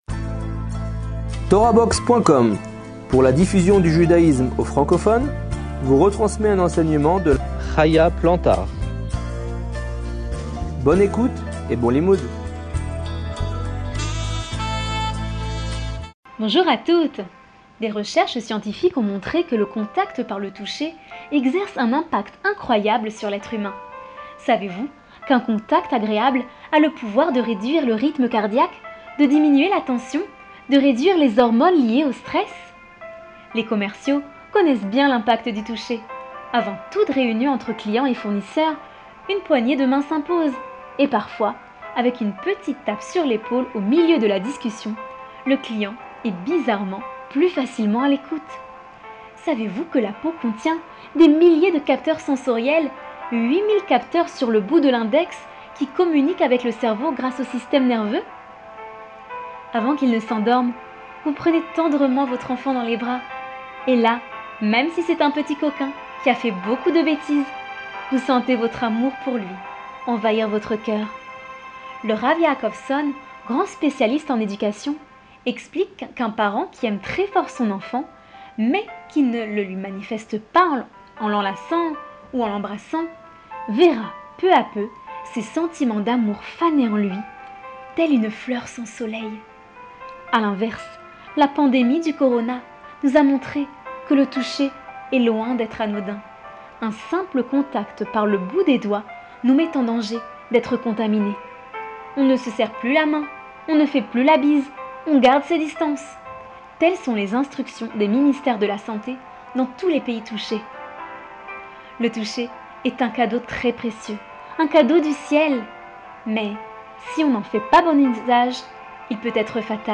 Cours audio MP3 de 5 minutes